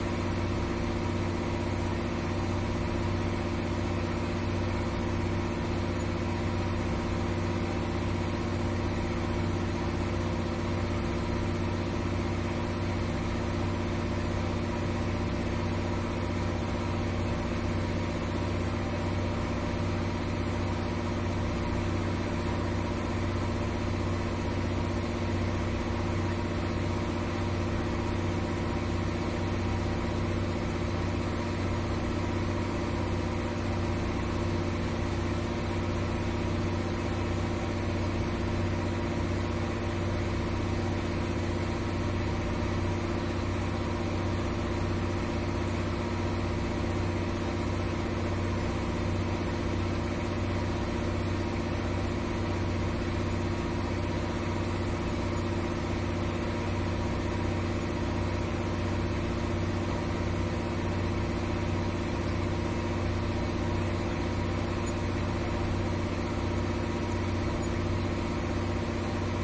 亚马逊 " 船亚马逊渡轮2层柴油驳船上的响声2
描述：船亚马逊渡轮2甲板柴油驳船大声
Tag: 驳船 柴油 机载 轮渡